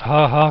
haha16b.wav